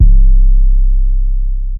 808 Metro Vault Low.wav